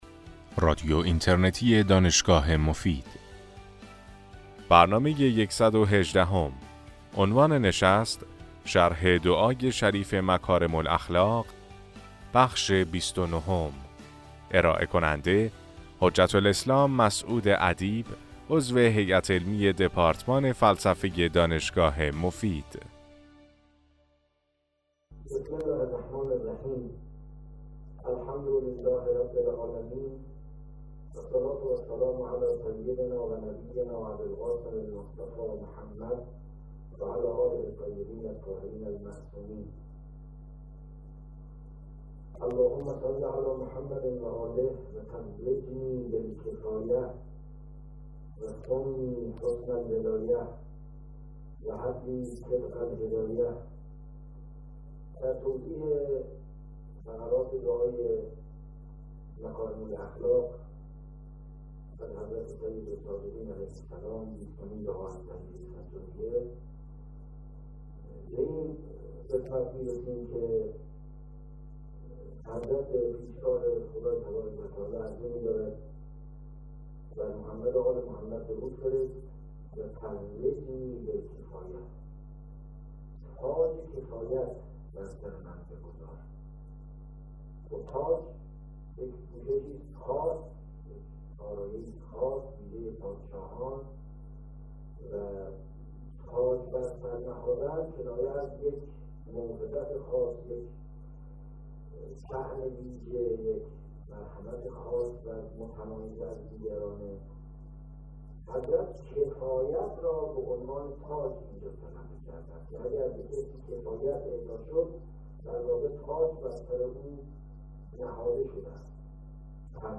این سلسله سخنرانی که در ماه رمضان سال 1395 ایراد شده است